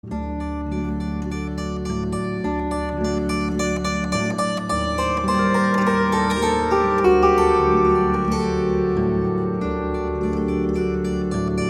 18 mélodies hébraïques à la cithare.
Musiques traditionnelles, adaptées pour cithare